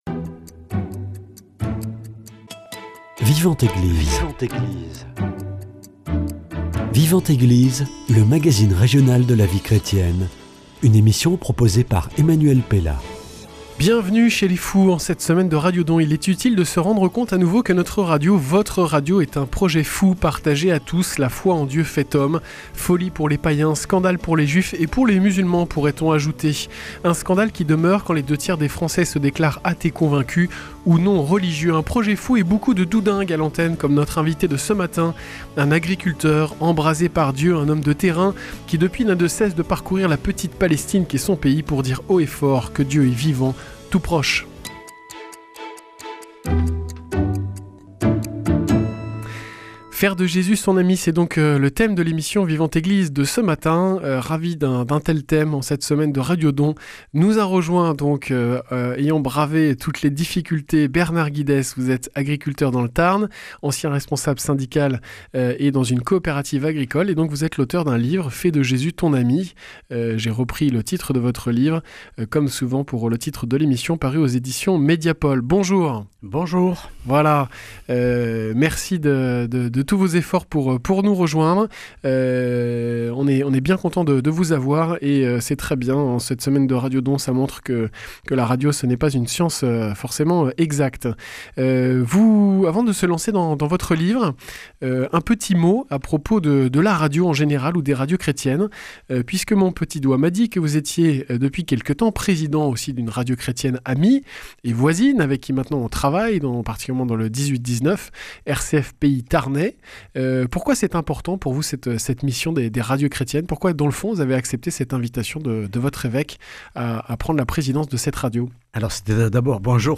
Notre invité de ce matin a fait le grand saut de la vie chrétienne : celui de la prière. Un agriculteur qui vous parle de Jésus comme s’il l’avait rencontré la veille.